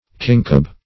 Search Result for " kincob" : The Collaborative International Dictionary of English v.0.48: Kincob \Kin"cob\, n. India silk brocaded with flowers in silver or gold.